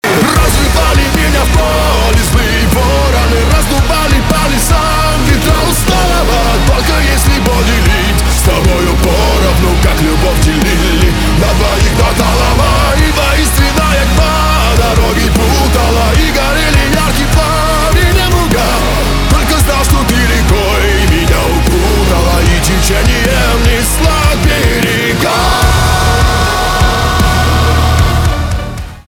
русский рок , гитара , барабаны , качающие
крики , чувственные